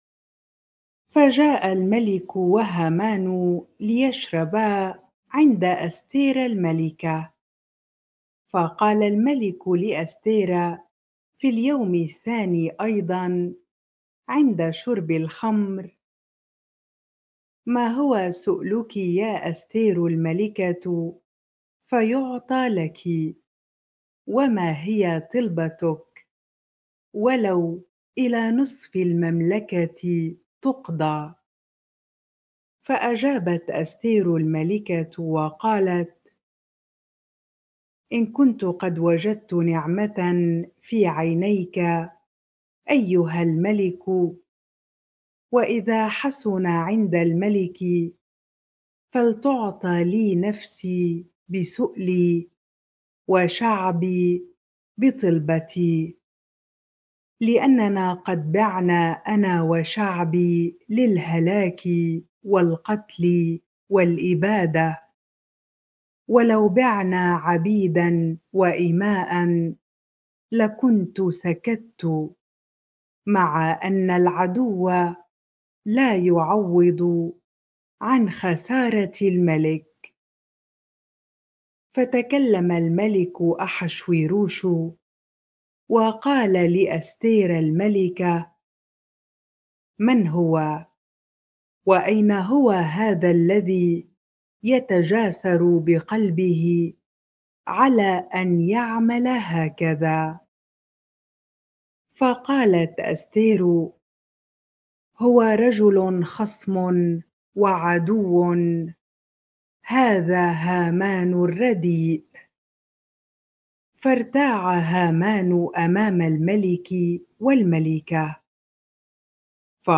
bible-reading-Esther 7 ar